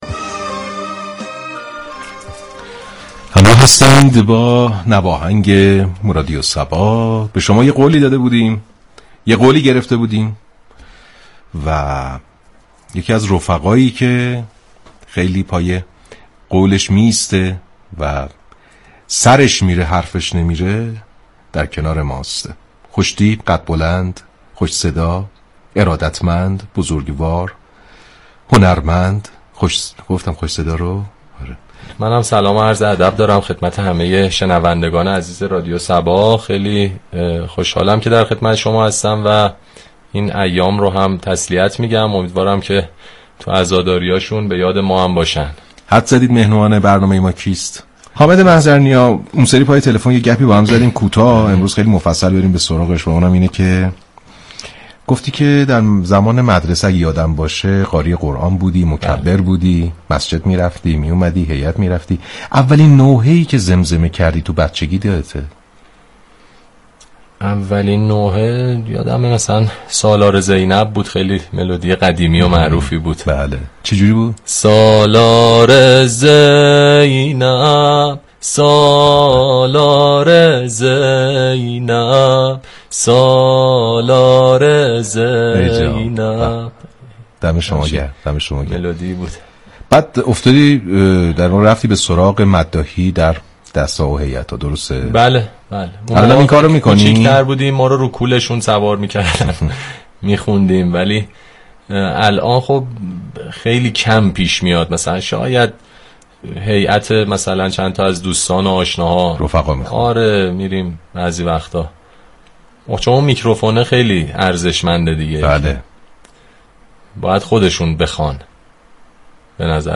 ویژه برنامه «نواهنگ» رادیو صبا به مناسبت ایام شهادت امام حسین (ع) با حضور حامدمحضرنیا راهی آنتن شد.
محضرنیا با اجرای زنده، بخش هایی از این ترانه را تقدیم دوستداران وعاشقان قمربنی هاشم كرد و در ادامه برنامه با مداحی و روایت های شنیدنی حال وهوای این برنامه را معنوی تركرد.